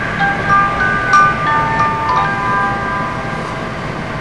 私が今までに収録してきた、ささやかな車内放送チャイム集です。
「のぞみ」途中駅到着前のチャイム(その1)[tec500b.wav/90KB]
上と同じときに収録したものですが、走行音の収録中にたまたま入っていたもので、音質に関しては とても悪いです。京都到着時に鳴っていたものを編集しました。